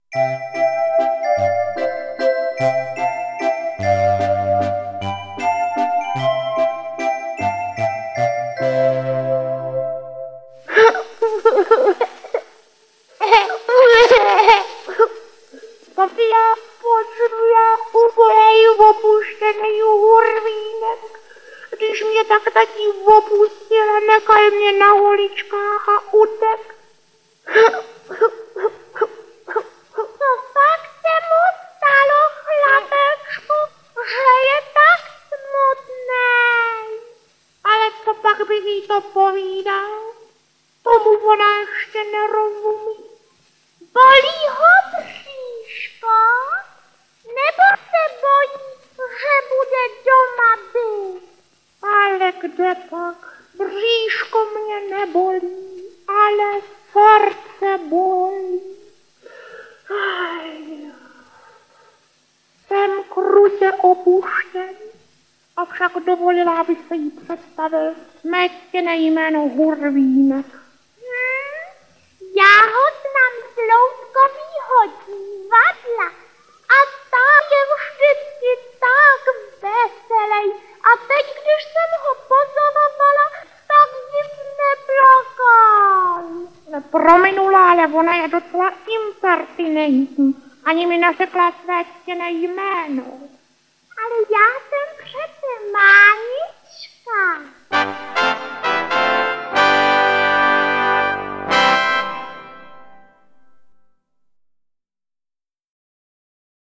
Ať žije Mánička! audiokniha
Ukázka z knihy